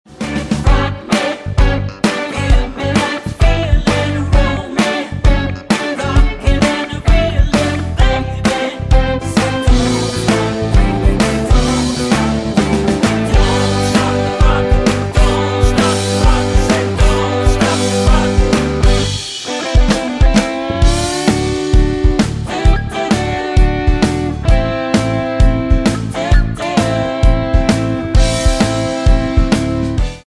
--> MP3 Demo abspielen...
Tonart:G mit Chor